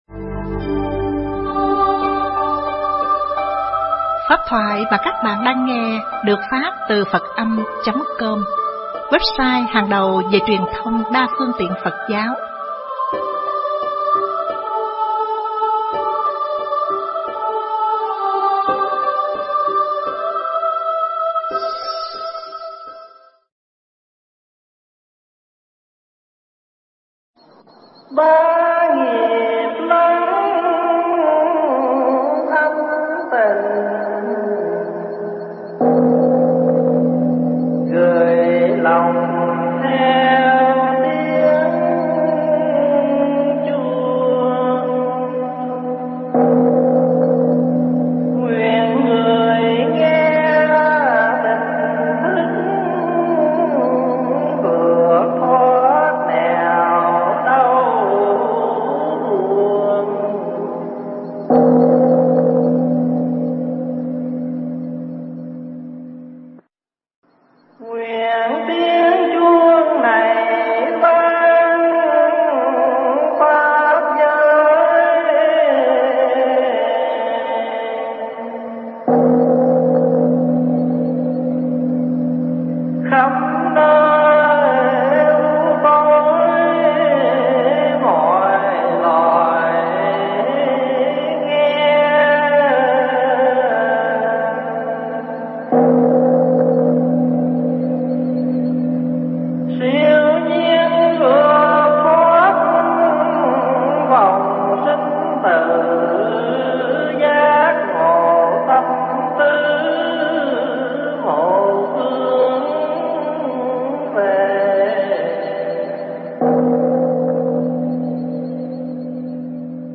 Nghe Mp3 thuyết pháp Bồ Tát Vượt Khó